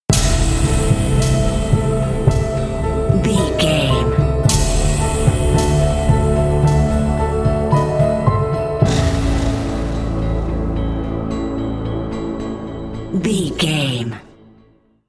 Thriller
Aeolian/Minor
Slow
synthesiser
electric piano
percussion
ominous
suspense
haunting
creepy